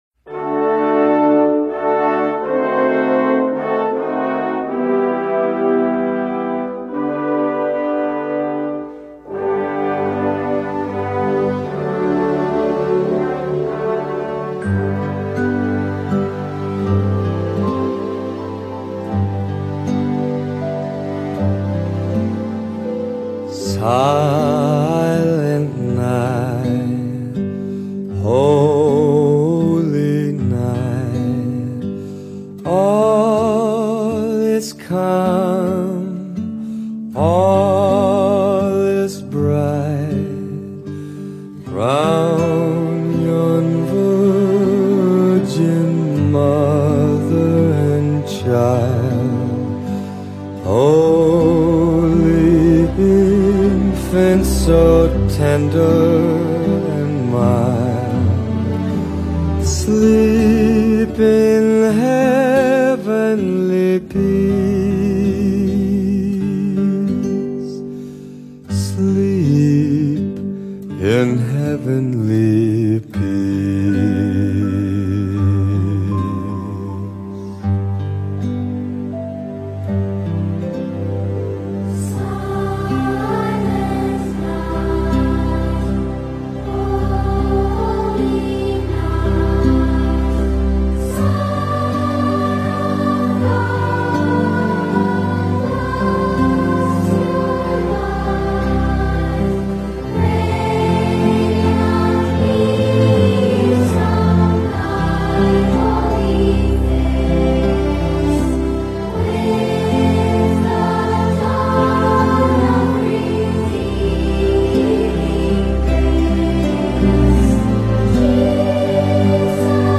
下面的音频为“平安夜”英文献诗：